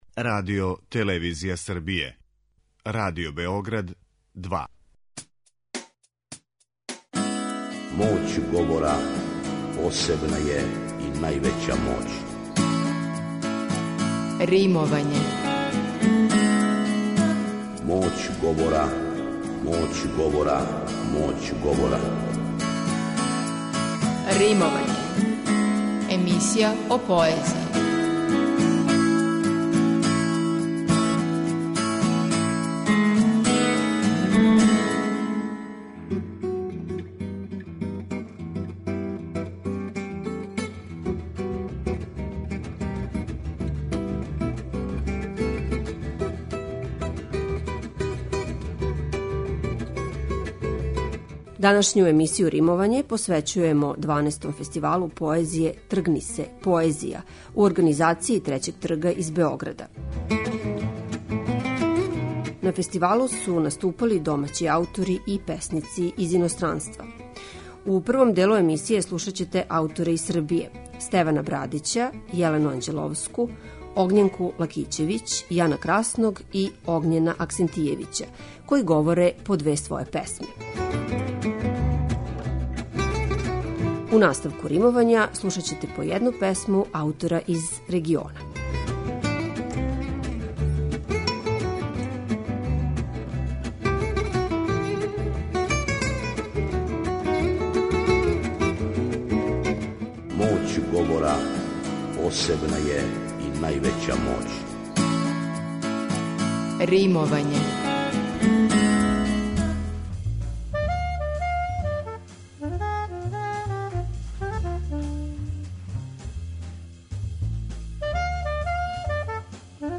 У данашњој емисији о поезији, слушаћете поезију учесника фестивала 'Тргни се, поезија!' у организацији 'Трећег трга' из Београда.